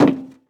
added stepping sounds
MetalSteps_01.wav